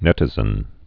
(nĕtĭ-zən)